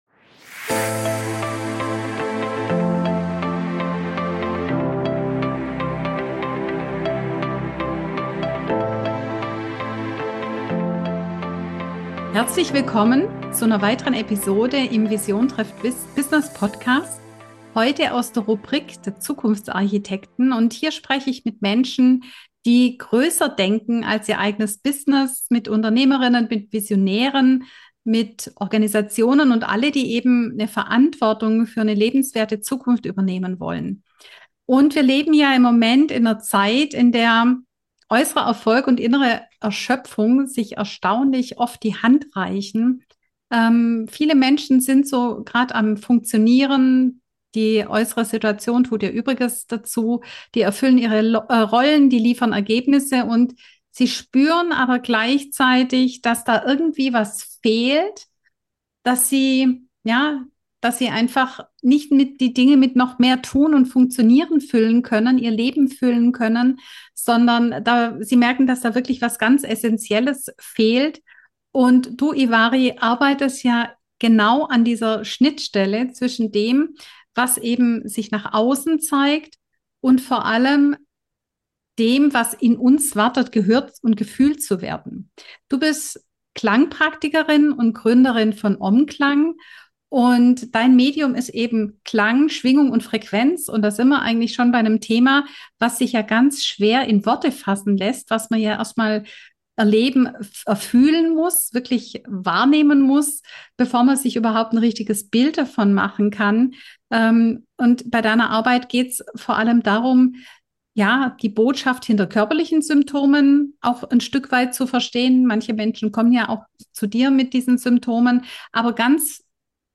In diesem Gespräch sprechen wir über die Frage, was es braucht, um wieder in Kontakt mit sich selbst zu kommen.